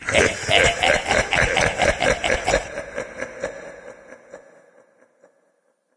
doom_laugh1.mp3